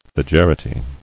[le·ger·i·ty]